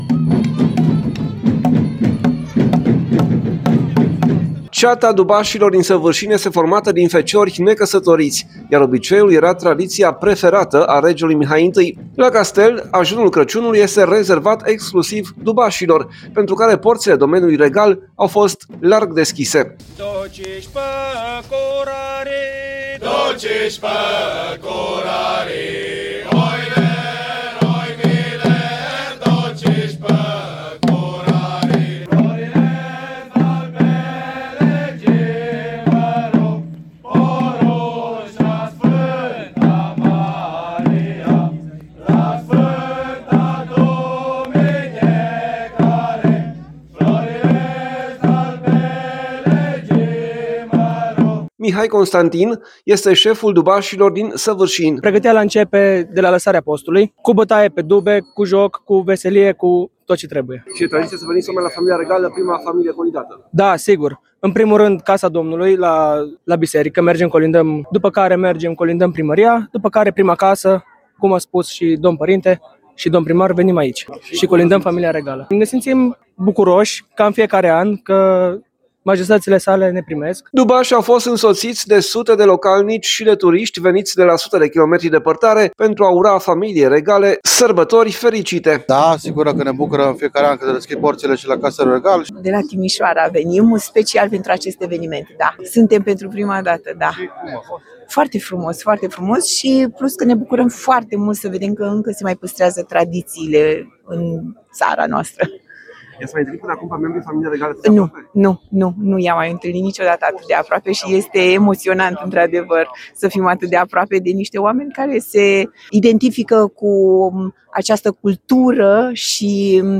La castel, Ajunul Crăciunului este rezervat exclusiv dubașilor, pentru care porțile domenului regal au fost larg deschise.
Dubașii au fost însoțiți de sute de localnici și de turiști veniți de la sute de kilometri departare pentru a ura familie regale sărbători fericite.